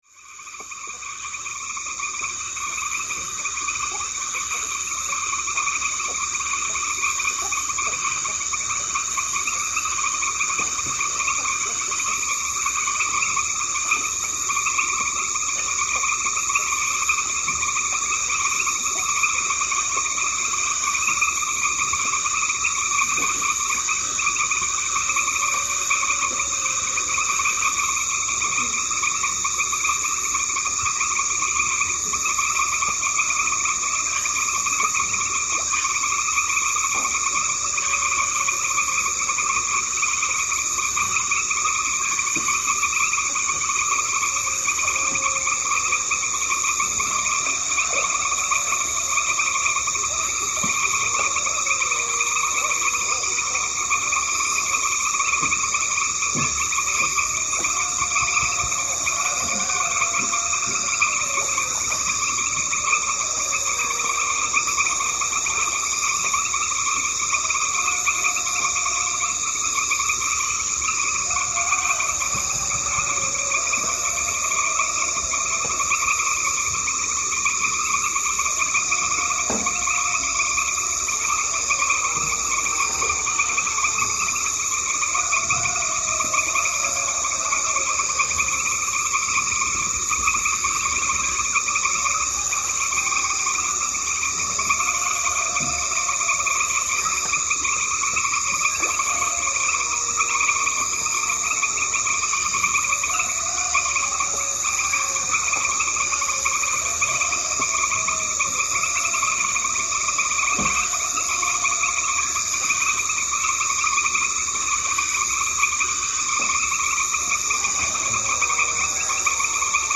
The Amazon's loud frogs
A pre-dawn soundscape dominated by the loud, relentless chorus of frogs, immersing you deeply in the heart of an indigenous community. The sounds get alive as soon as darkness falls, enveloping you in an unbroken, ever-present atmosphere.
Deep within the Colombian Amazon, where it meets the Amazonian region of Peru, the vibrant frog calls resonate just before the first light of dawn.